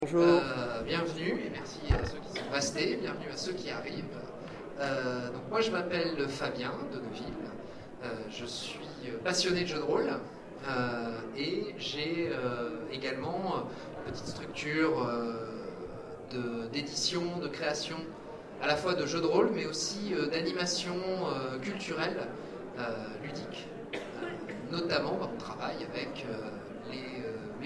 Zone Franche 2014 : Conférence Le jeu de rôle en bibliothèque